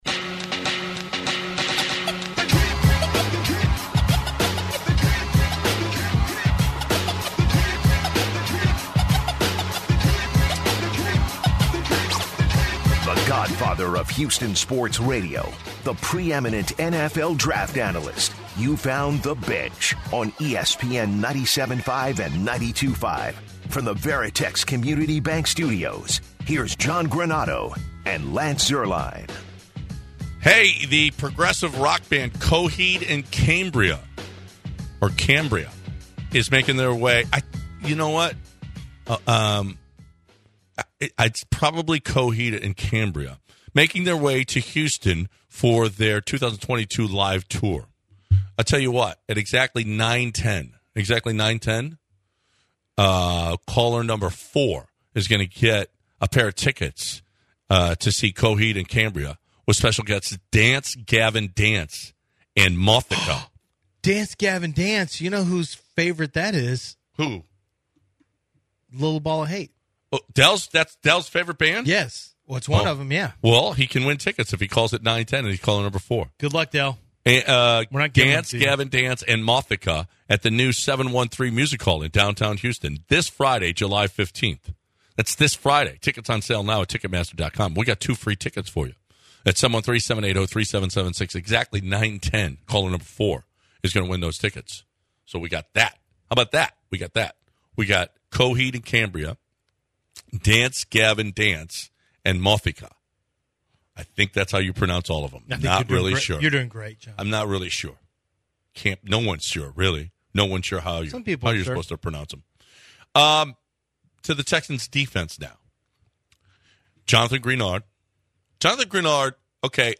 Next the guys talk to a guest about the Astros regarding lots of things with the team.